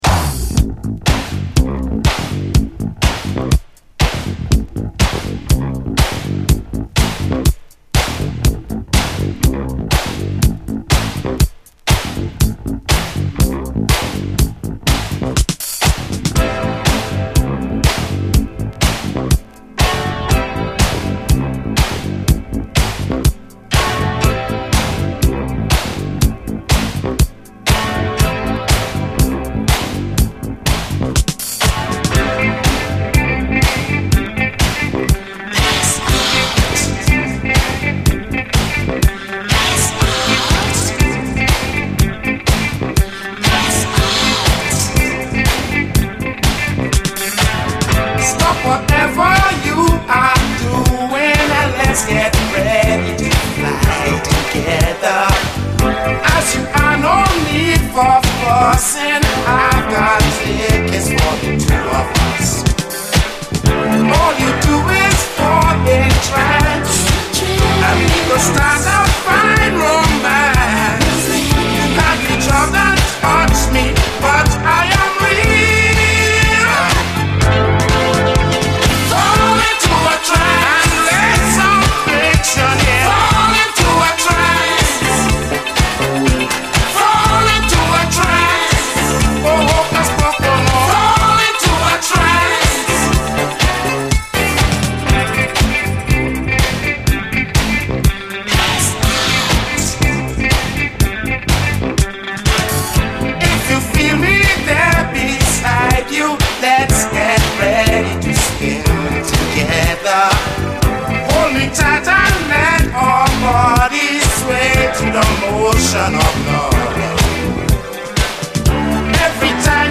ITALO DISCO Archive
ソウルフル＆ファンシーなイタリア産ディスコ・クラシック！